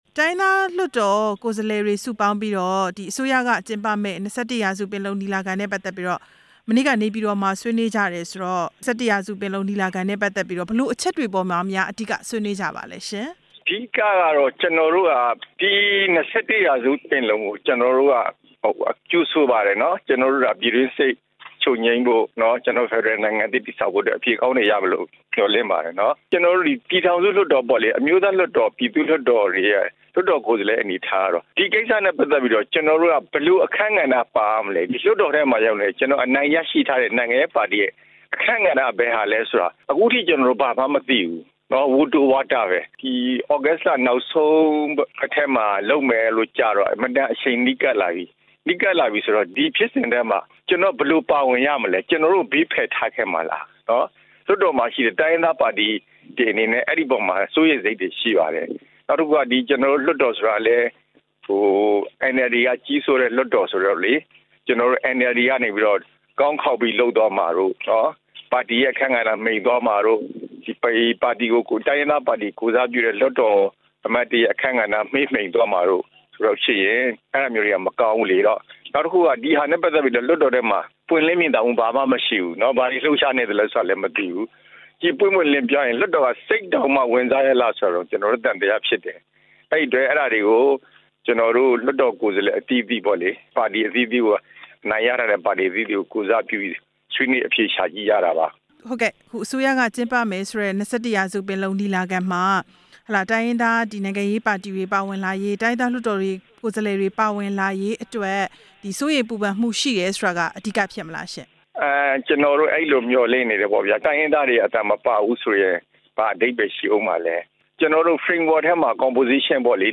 မြန်မာနိုင်ငံရဲ့ ငြိမ်းချမ်းရေးလုပ်ငန်းစဉ်တွေမှာ ဒီ့ထက်ပိုပြီး ပွင့်လင်းမြင်သာမှု ရှိစေချင်တယ်လို့ ရခိုင်အမျိုးသားပါတီ ANP လွှတ်တော်ကိုယ်စားလှယ် ဦးဦးလှစောက ပြောပါတယ်။ နေပြည်တော်မှာ မနေ့က ၂၁ ရာစု ပင်လုံညီလာခံနဲ့ ပတ်သက်လို့ တိုင်းရင်းသားလွှတ်တော်ကိုယ်စားလှယ်တွေ တွေဆုံဆွေးနွေးကြတာနဲ့ ပတ်သက်လို့ ဆက်သွယ်မေးမြန်းစဉ် အခုလို ပြောလိုက်တာပါ၊